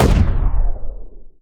poly_explosion_medium2.wav